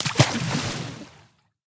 Minecraft Version Minecraft Version 1.21.5 Latest Release | Latest Snapshot 1.21.5 / assets / minecraft / sounds / mob / dolphin / splash1.ogg Compare With Compare With Latest Release | Latest Snapshot
splash1.ogg